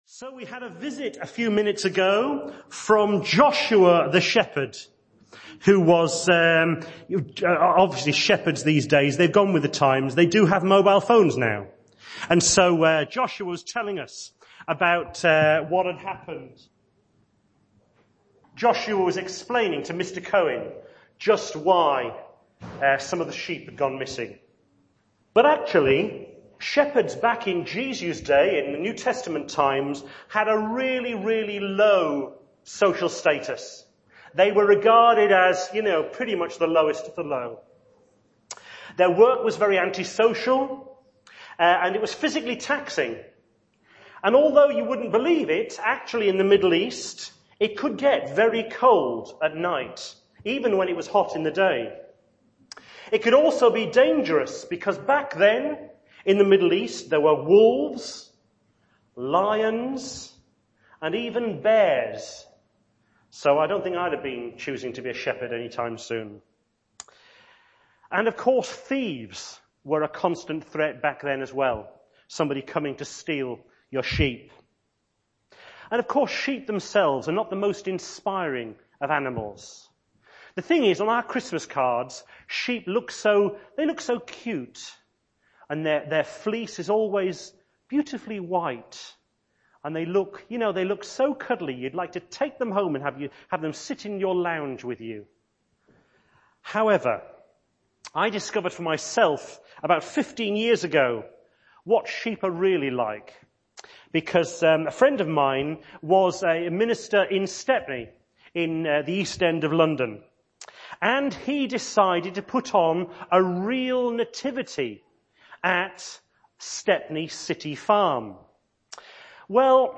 Carol Service message